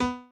b_pianochord_v100l24o4b.ogg